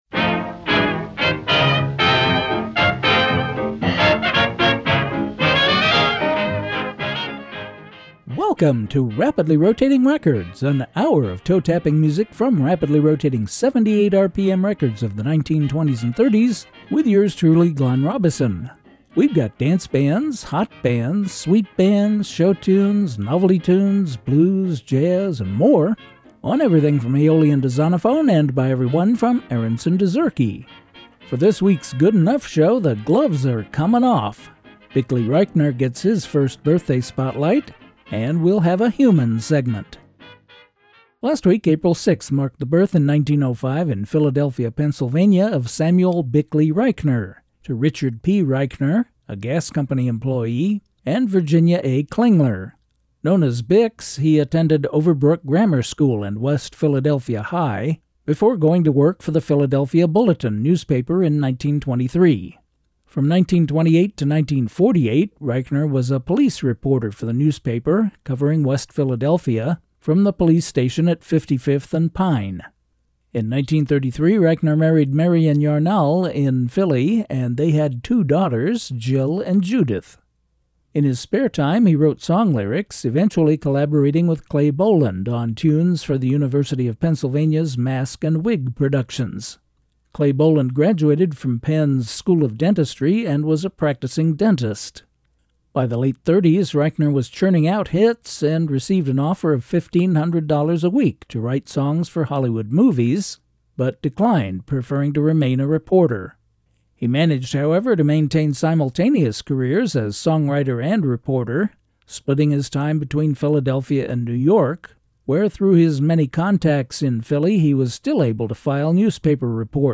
Hammond Electric Organ Solo